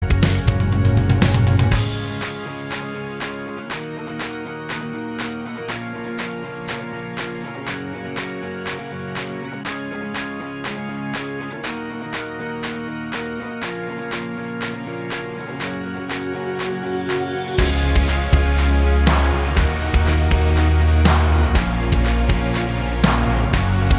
Minus All Guitars Pop (2010s) 3:18 Buy £1.50